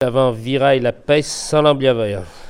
Saint-Jean-de-Monts
Langue Maraîchin
Catégorie Locution